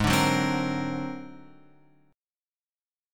G# 9th Flat 5th